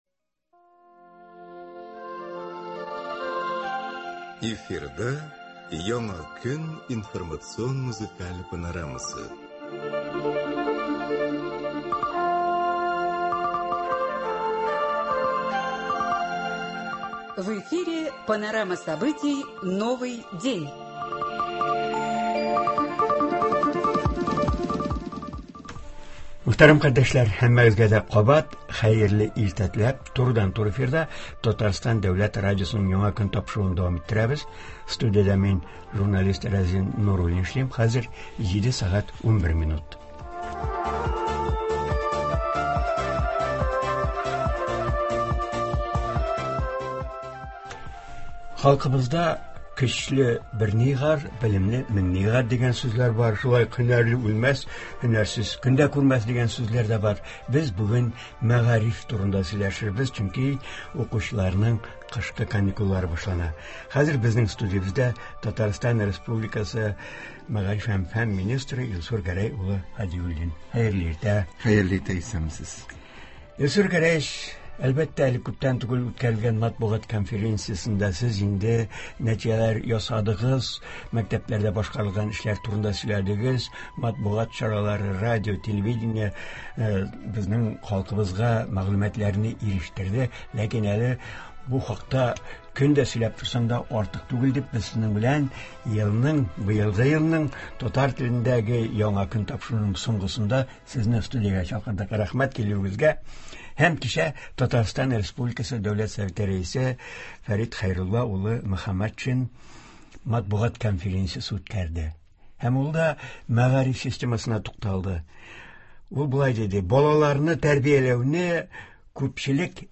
Туры эфир (28.12.22)
Болар хакында турыдан-туры эфирда Татарстан республикасы мәгариф һәм фән министры Илсур Һадиуллин сөйләячәк, уку елының икенче яртысында алда торган бурычларга тукталачак, тыңлаучыларны кызыксындырган сорауларга җавап бирәчәк.